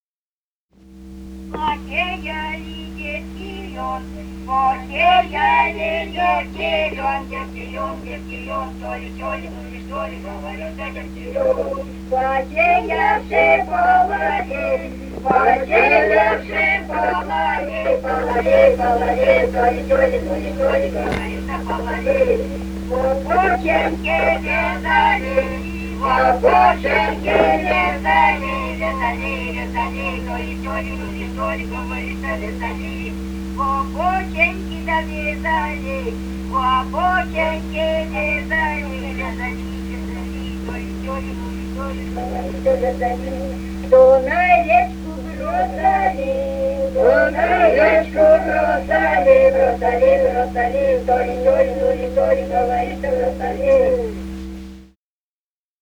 «Посеяли девки лён» (хороводная). с. Денисово Дзержинского района. Пела группа колхозников